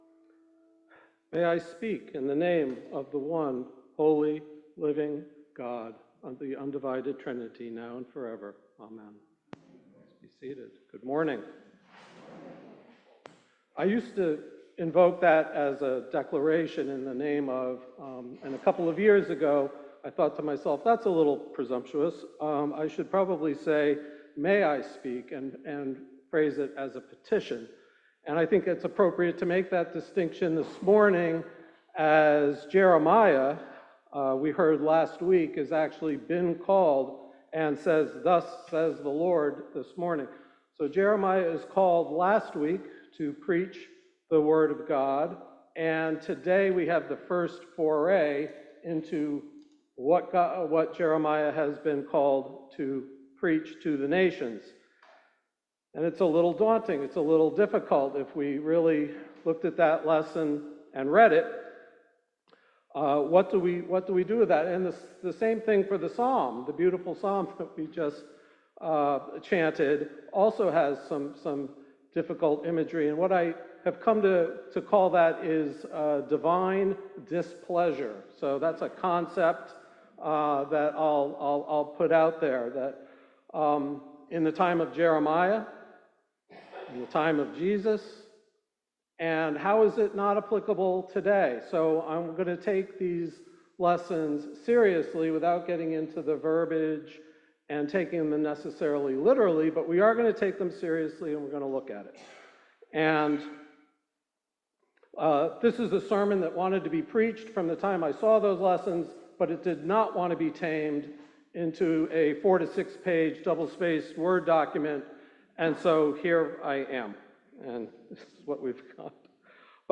Sermon-8-31.mp3